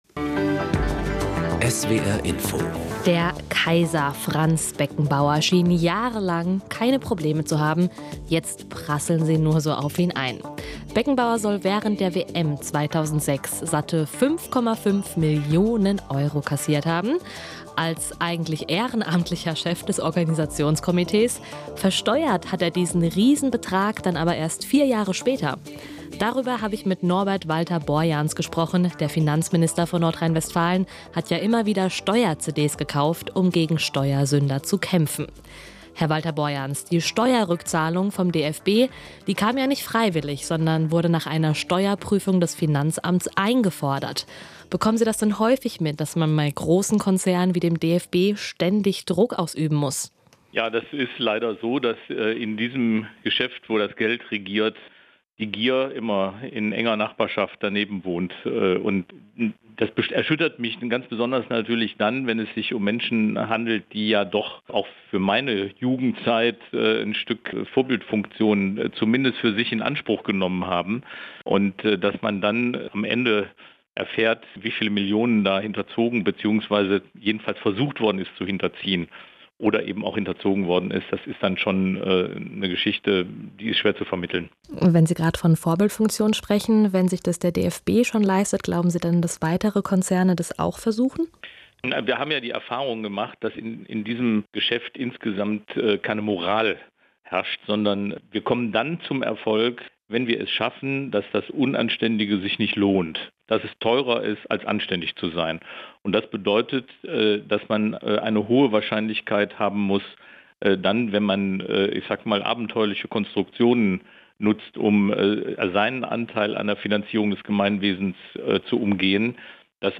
Interview beim SWRInfo zum Fall Beckenbauer und Steuerhinterziehung